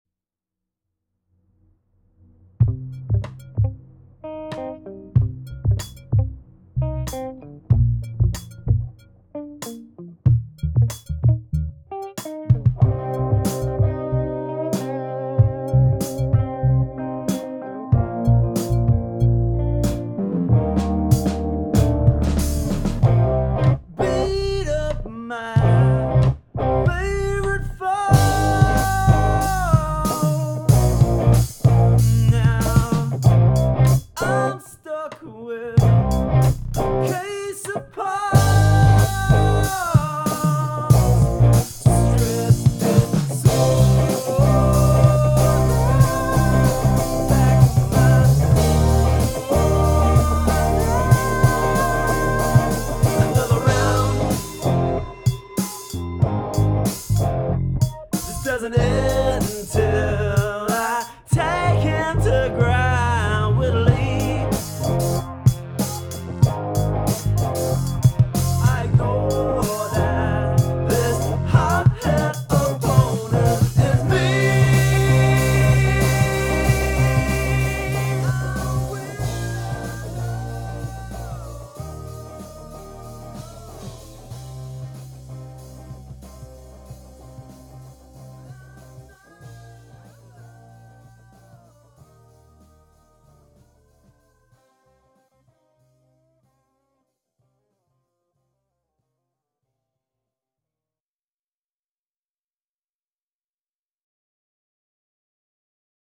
new demo